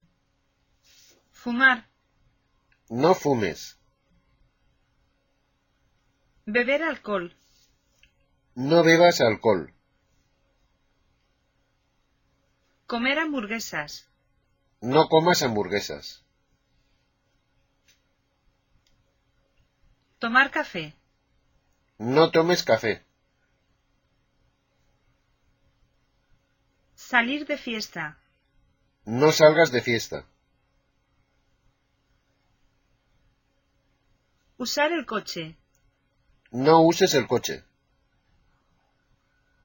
Listen and write what the doctor says you shouldn't do in order to live a healthy life.